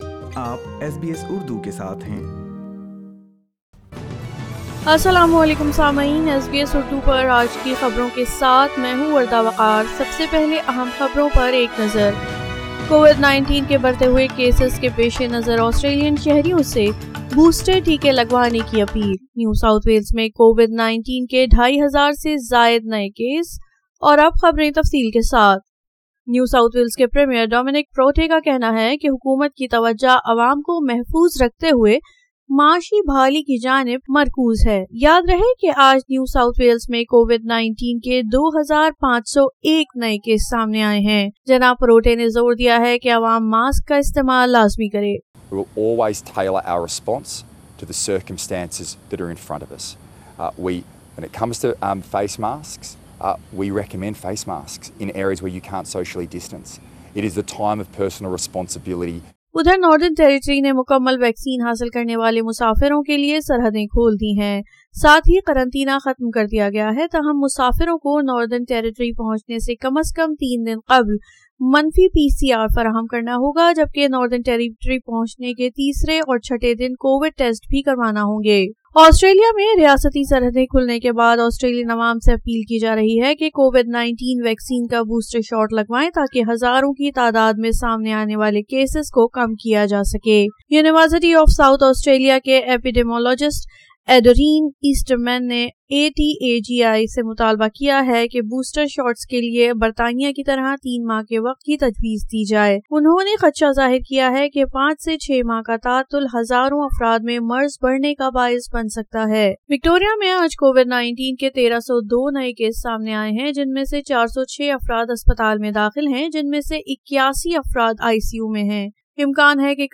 SBS Urdu News 20 December 2021